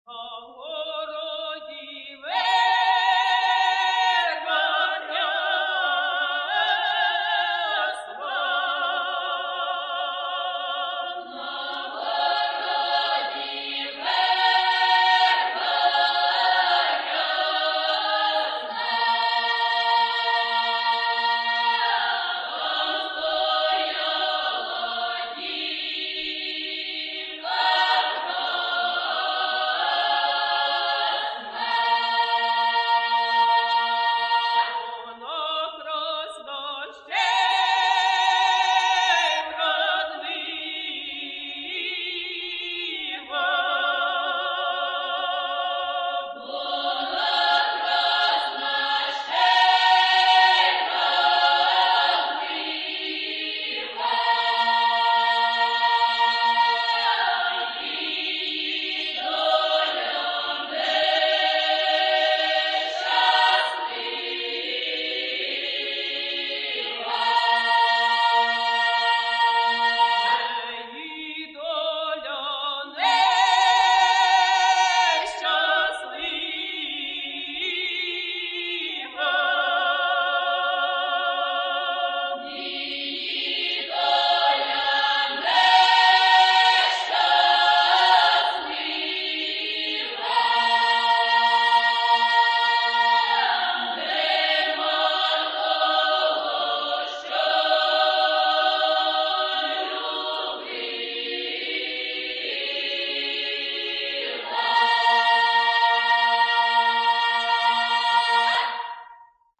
Украинская народная песня